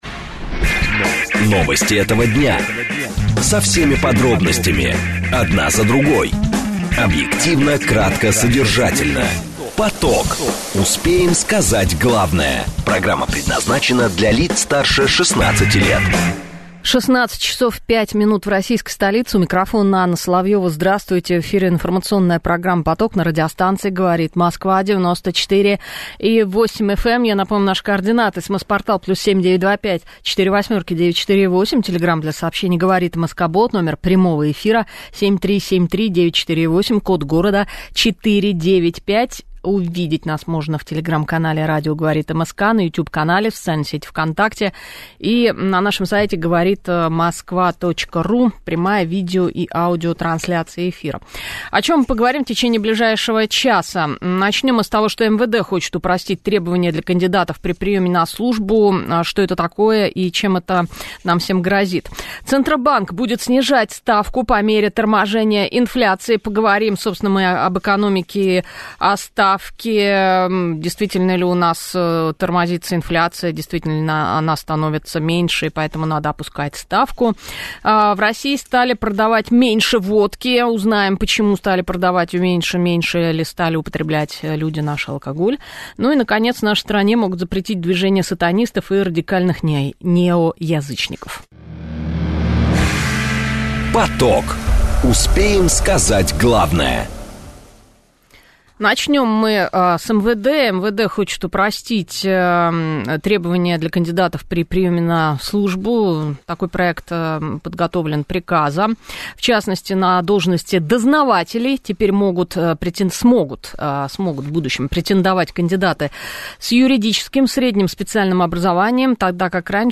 В прямом эфире радио "Говорит Москва" рассказал о возможном запрете в Российской Федерации религиозных (?) организаций сатанистов и некоторых неоязычников.